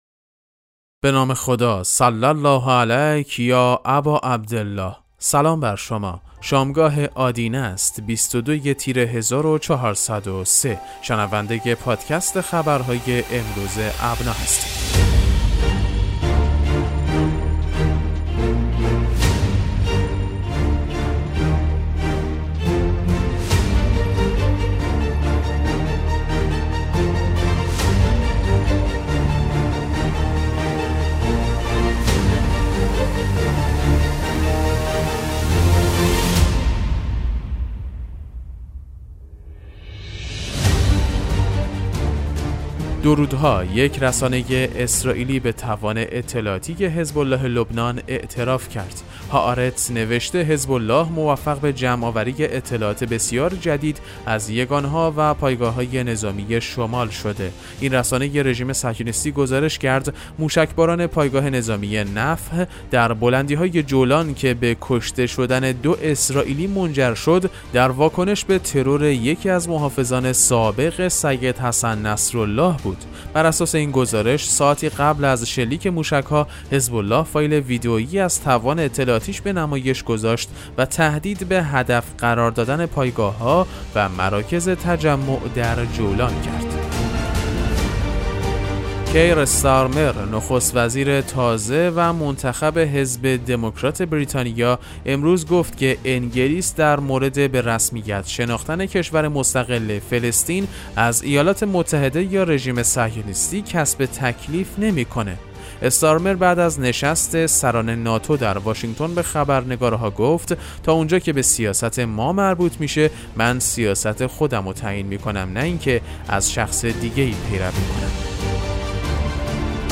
پادکست مهم‌ترین اخبار ابنا فارسی ــ 22 تیر 1403